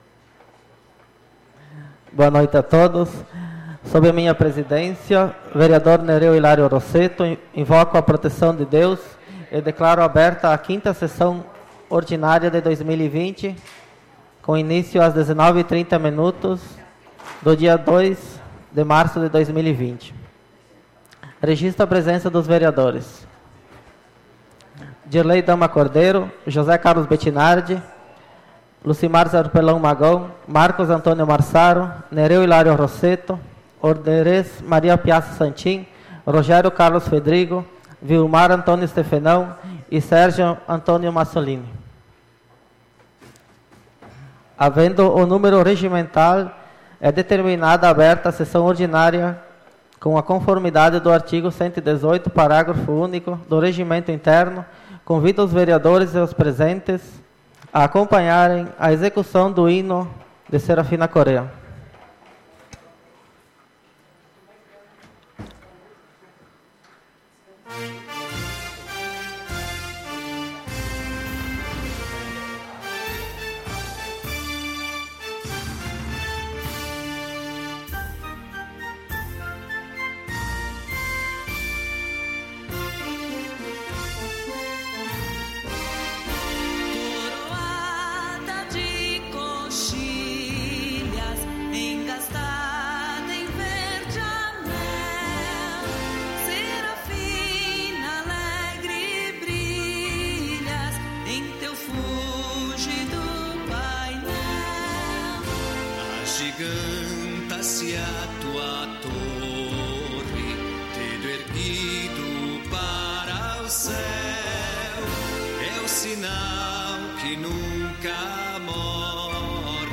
SAPL - Câmara de Vereadores de Serafina Corrêa - RS